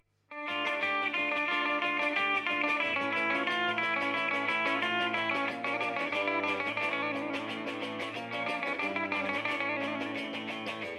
Αναλογική αισθητική στην ψηφιακή εποχή: Συγκριτική μελέτη αναλογικών και ψηφιακών τεχνικών στην ηχογράφηση και μίξη της ηλεκτρικής κιθάρας